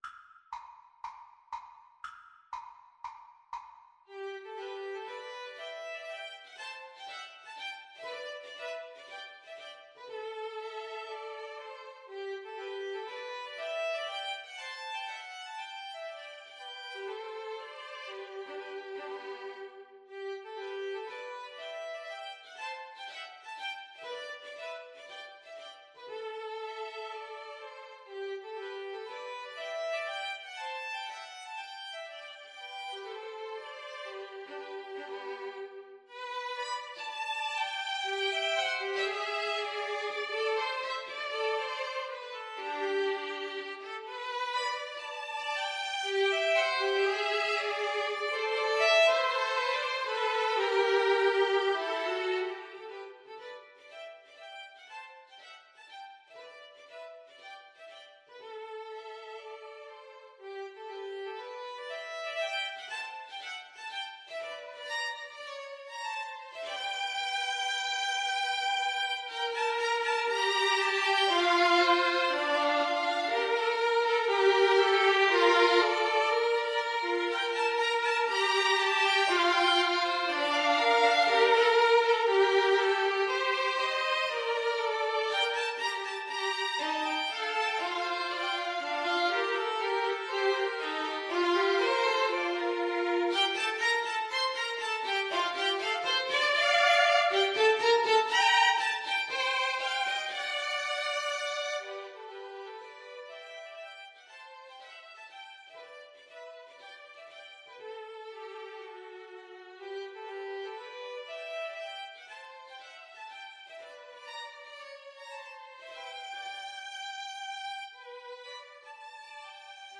Classical Dvořák, Antonín Humoresque Op. 101, No. 7 Violin Trio version
G major (Sounding Pitch) (View more G major Music for Violin Trio )
= 60 Poco lento e grazioso